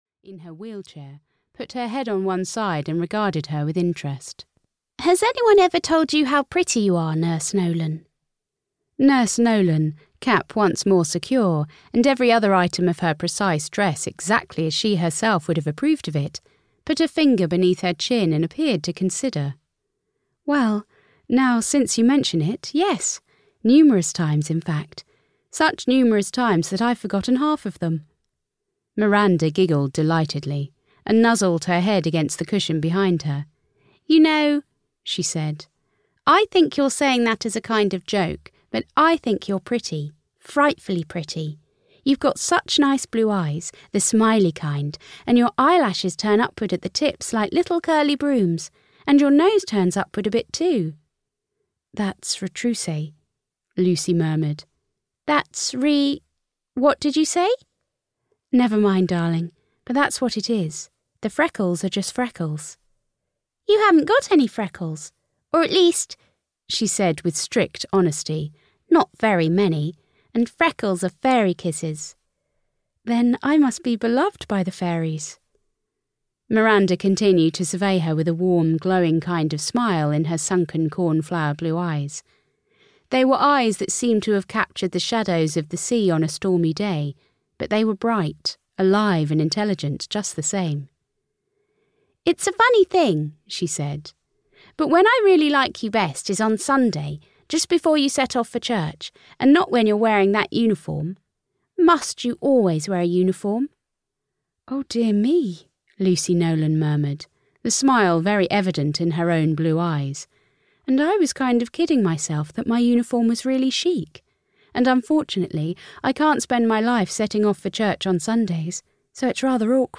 Dear Tiberius (EN) audiokniha
Ukázka z knihy